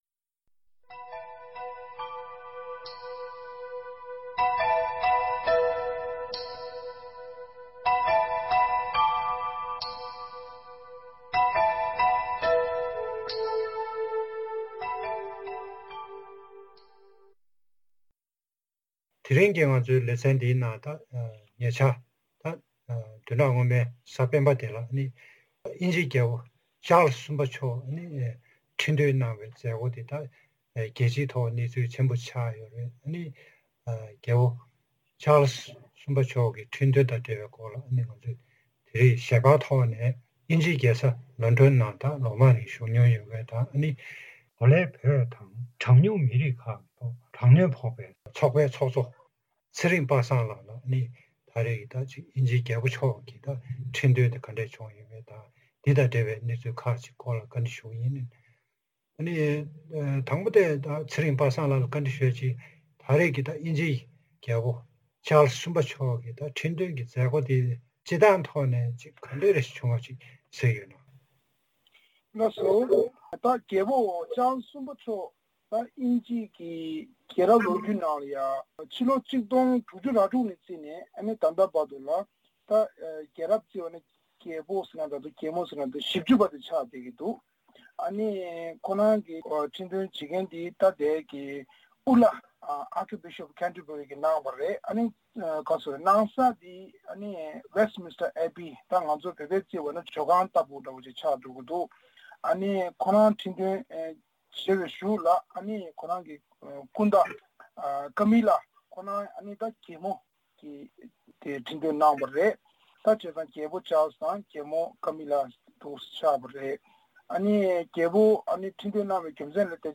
བཀའ་འདྲི་ཞུས་པ།